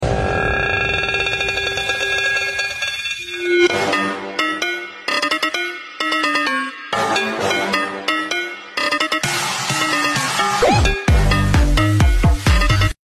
Aquarium Sound Effects Free Download